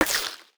UIClick_Menu Select Gravel 01.wav